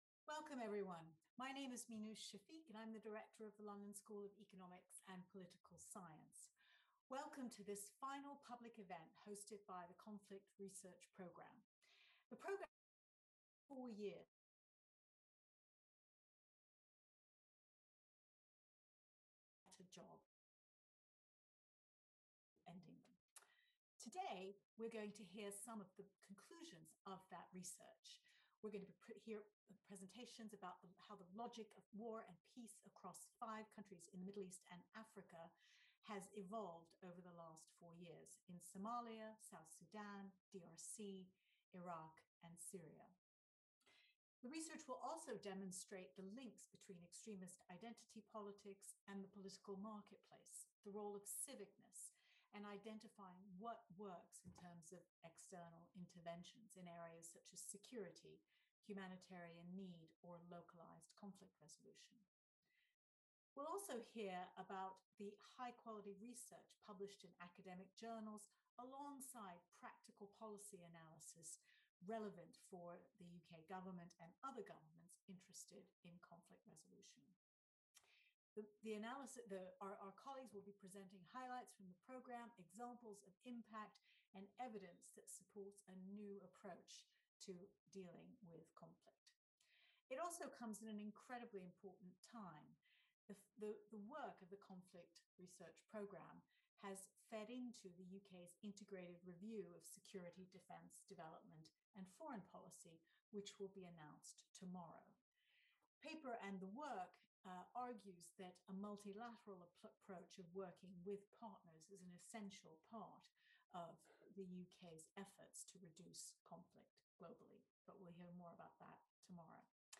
After four years of researching violence and conflict across Africa and the Middle East, what have we learned? Catch up on the concluding event of the Conflict Research Programme.